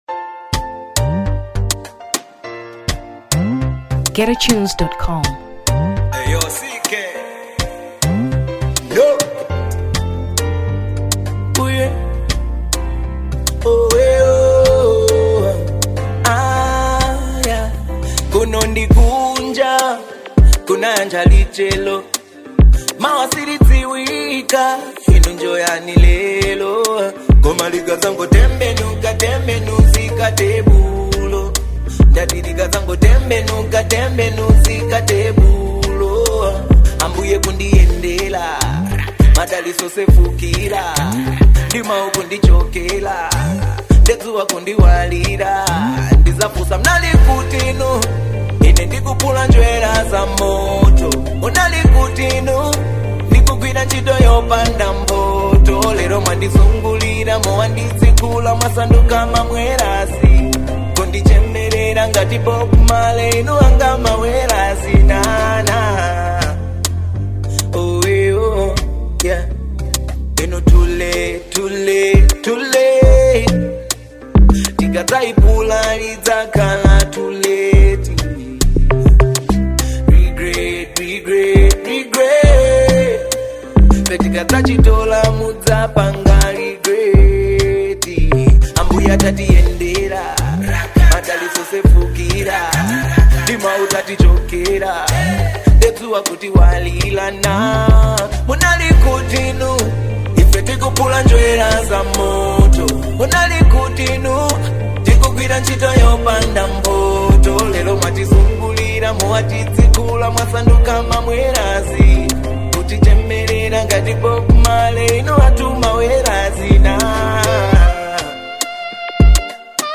Afro 2023 Malawi